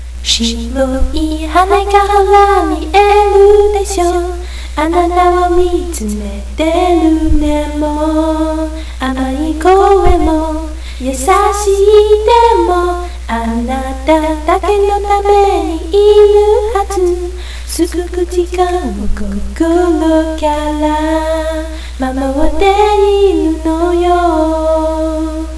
- - v o c a l    .w a v - -